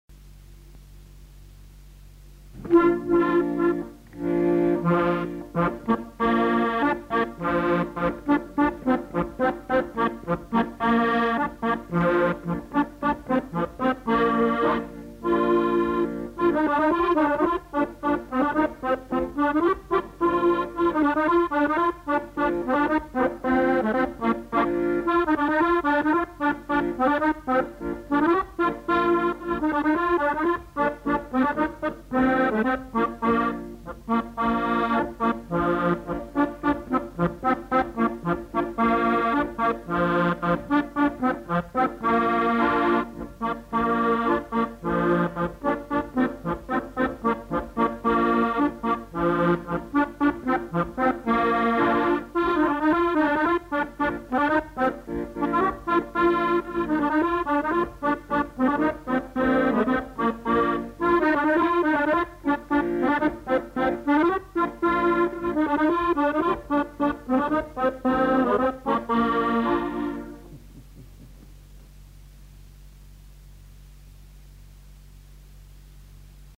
Aire culturelle : Haut-Agenais
Genre : morceau instrumental
Instrument de musique : accordéon chromatique
Danse : congo